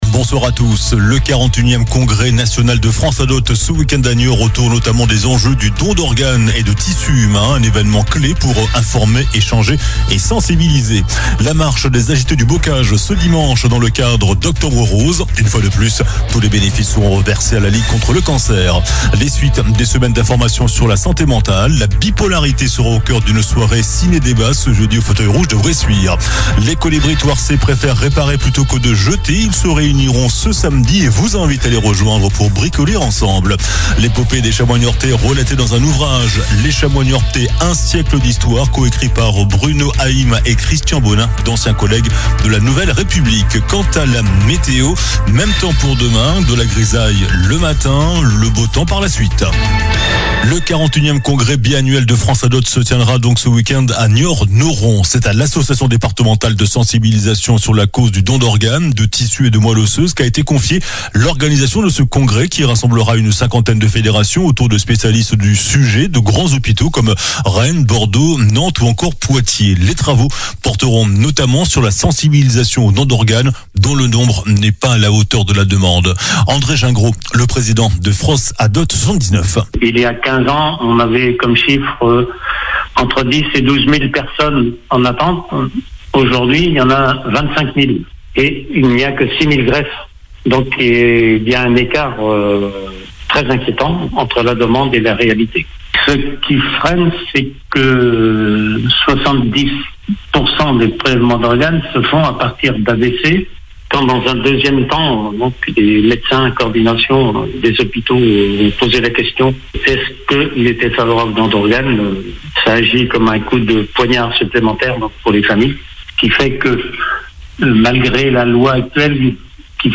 Journal du jeudi 16 octobre (soir)